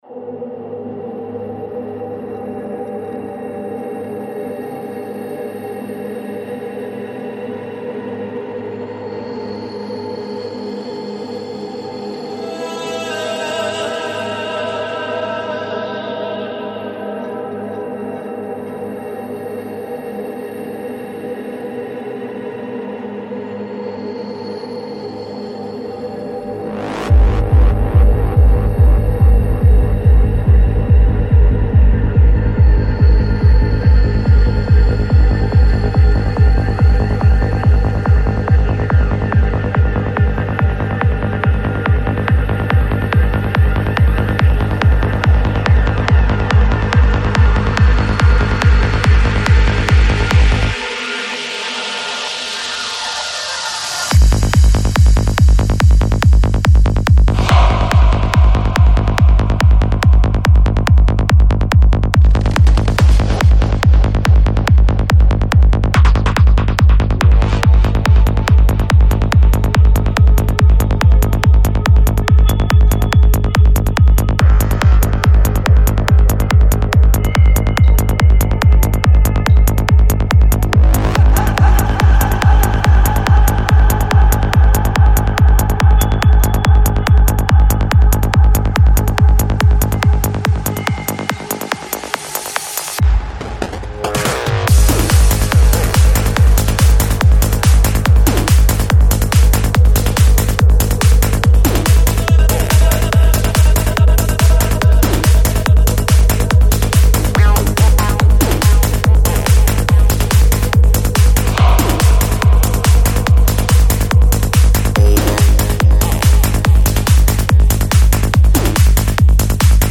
Psy-Trance